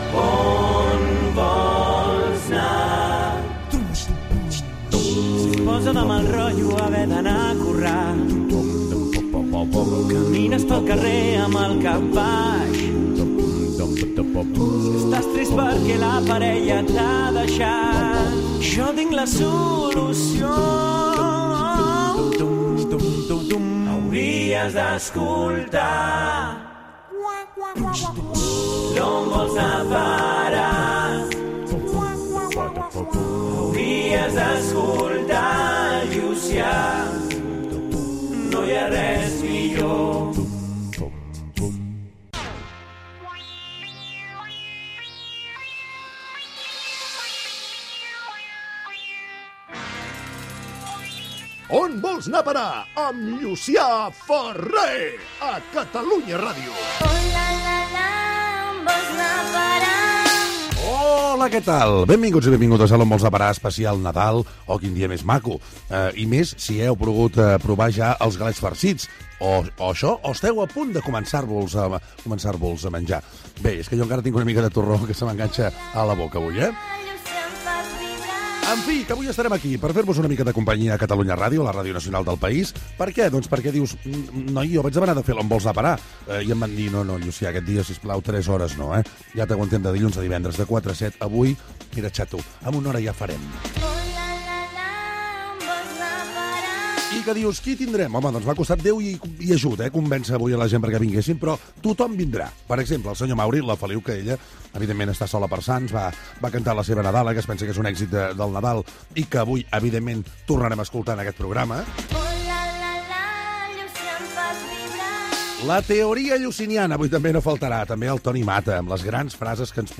Cançó del programa, identifciació, presentació de l'especial Nadal, sumari de continguts,"L'estadística del dia" sobre dades del consum d'espelmes, les cartes als Reis i els carters reials
Entreteniment